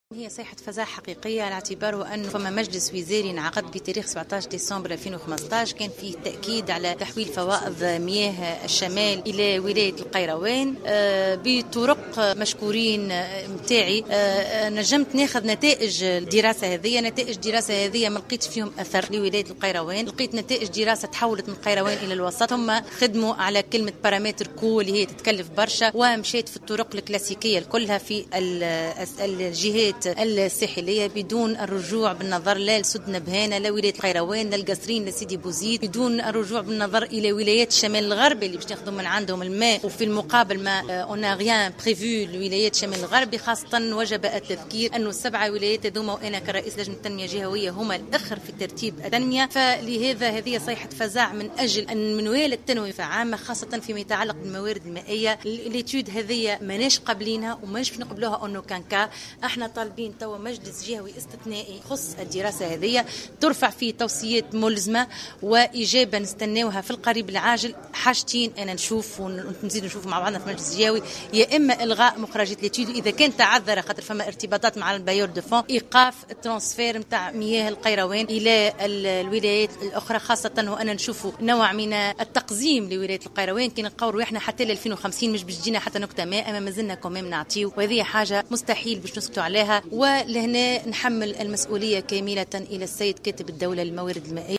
أكدت النائبة عن نداء تونس انس الحطاب خلال ندوة صحفية اليوم الأحد أنها اطلعت على مضمون الدراسة الفنية من أجل جلب مياه الشمال إلى الولايات الساحلية وأنها تفطنت إلى استبعاد ولاية القيروان من هذا المشروع مشيرة إلى أنها قررت اطلاق صيحة فزع من حرمان ولاية القيروان و ولايات الشمال الغربي و ولايات الوسط من هذا الموارد المائية.